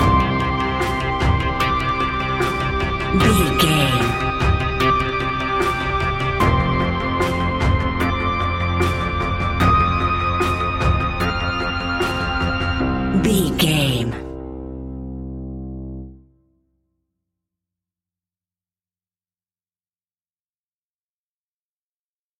In-crescendo
Thriller
Aeolian/Minor
ominous
dark
haunting
eerie
synthesiser
drums
instrumentals
horror music